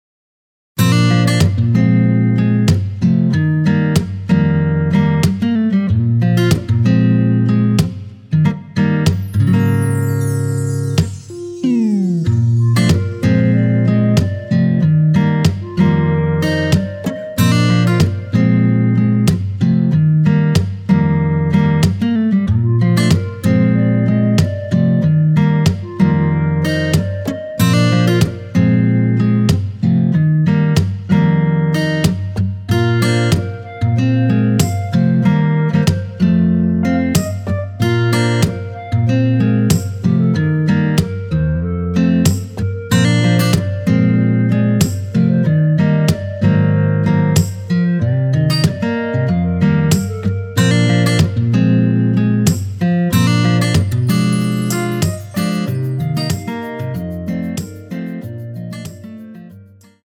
원키에서(-3)내린 멜로디 포함된 MR 입니다.(미리듣기 확인)
Bb
멜로디 MR이라고 합니다.
앞부분30초, 뒷부분30초씩 편집해서 올려 드리고 있습니다.
중간에 음이 끈어지고 다시 나오는 이유는